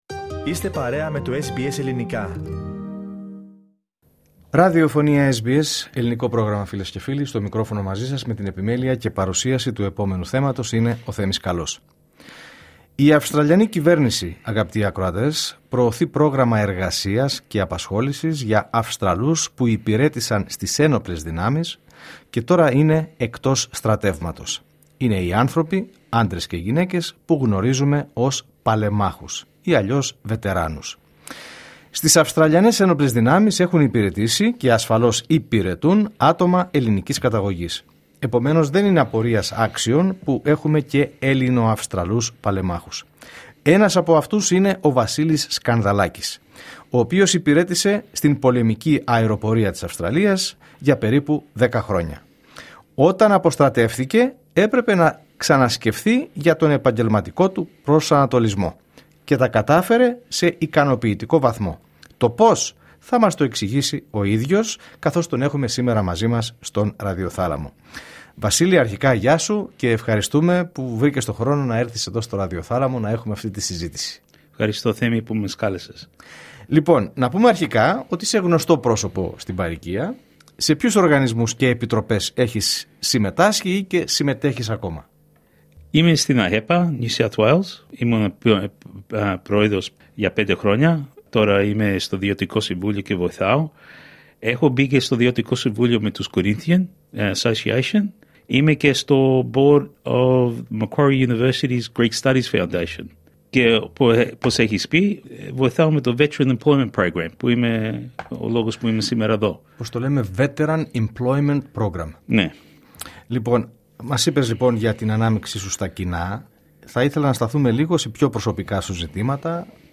Τα χρόνια του στις Ένοπλές Δυνάμεις ήταν γεμάτα εμπειρίες, με σημαντικά καθήκοντα και αξέχαστες στιγμές, όπως μας περιγράφει ο ίδιος σε μια εκ βαθέων συνέντευξη.